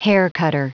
Prononciation du mot haircutter en anglais (fichier audio)
Prononciation du mot : haircutter